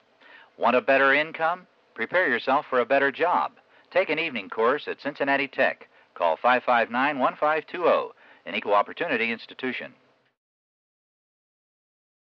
The audio tracks below are examples of radio advertising produced by the college in the 1970s. This series of ads, directed toward non-traditional students, promotes the availability of evening courses at CTC.